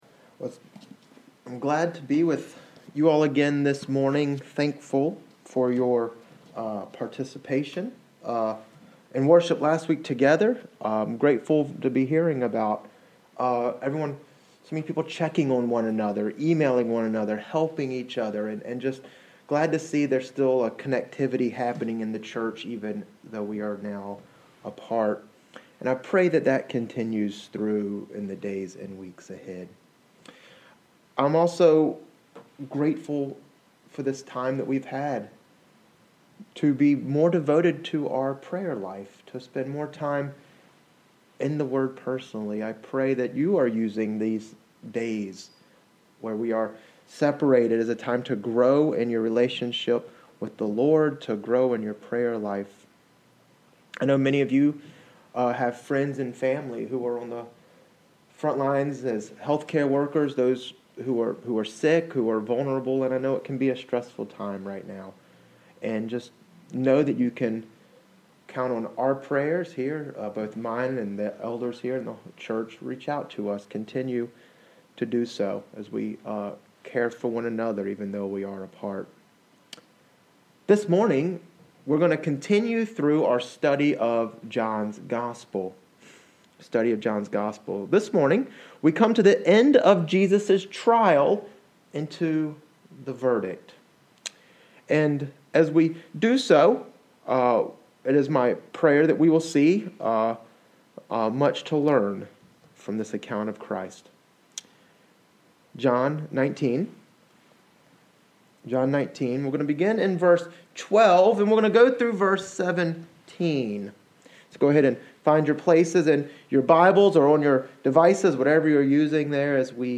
3.29.20_Sermon-Audio-.mp3